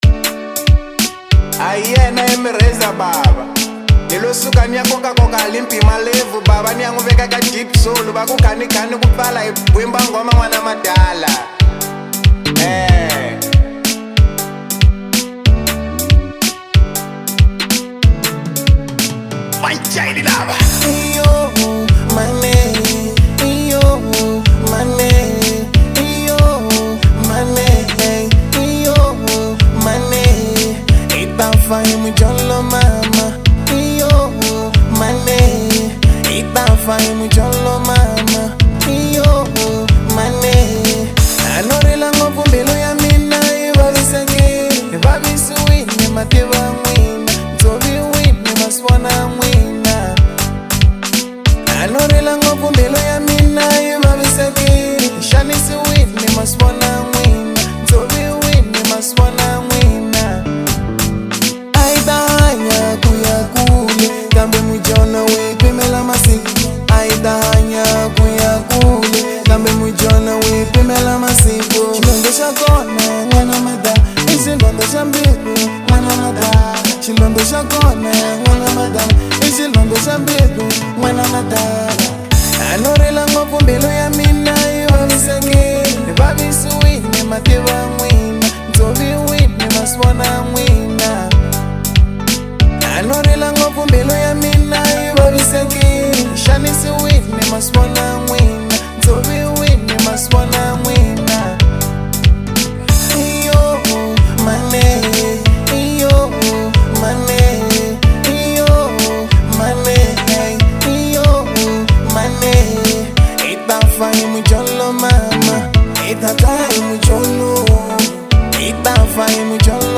04:29 Genre : African Disco Size